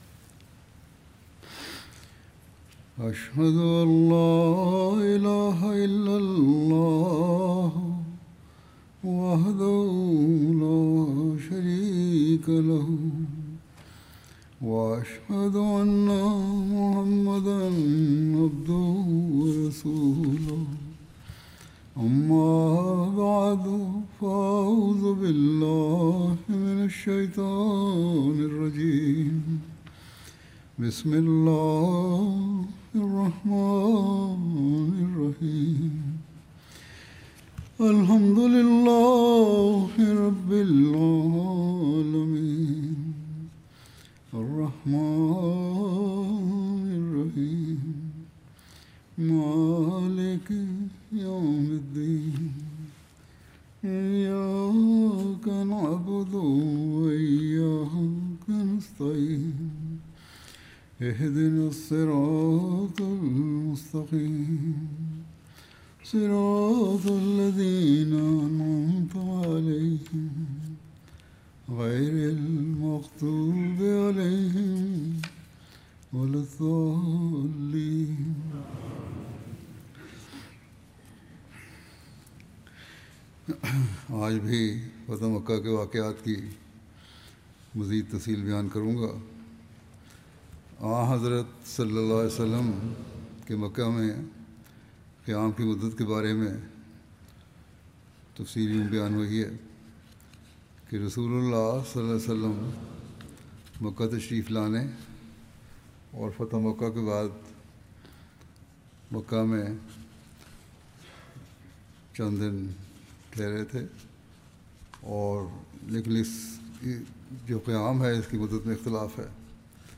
Urdu Friday Sermon by Head of Ahmadiyya Muslim Community
Urdu Friday Sermon delivered by Khalifatul Masih